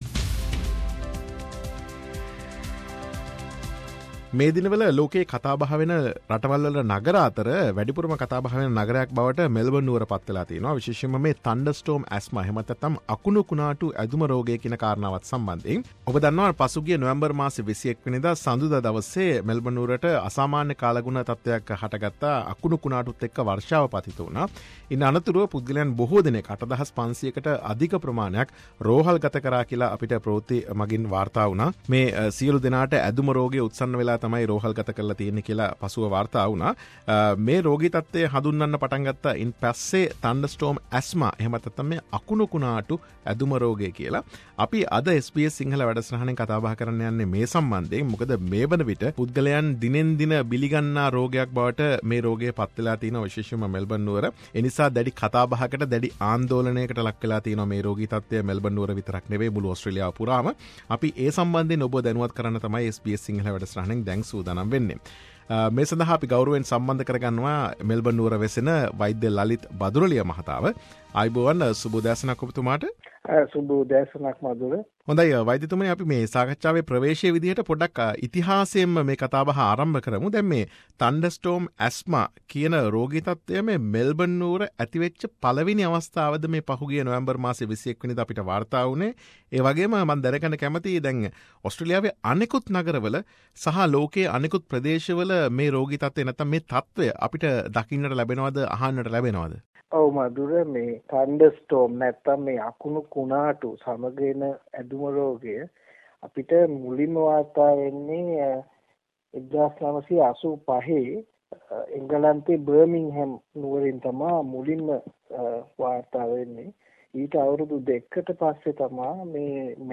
විශේෂ සාකච්ඡාව.